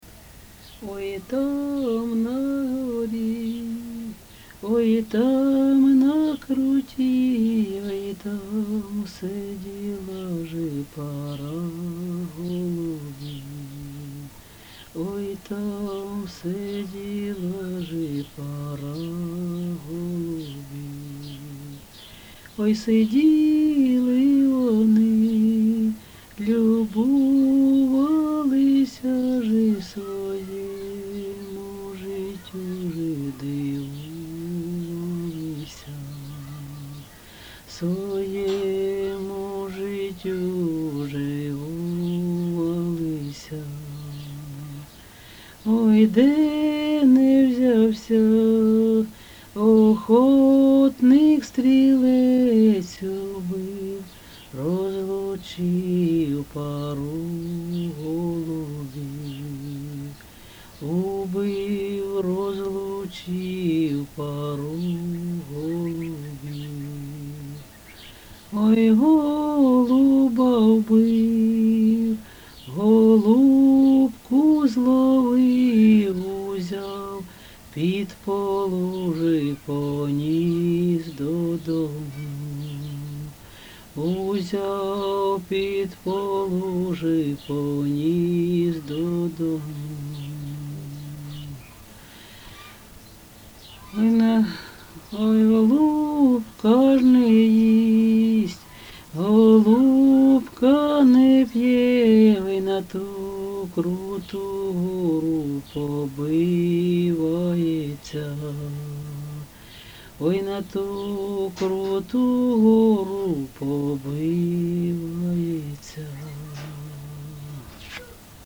ЖанрБалади
Місце записус. Серебрянка, Артемівський (Бахмутський) район, Донецька обл., Україна, Слобожанщина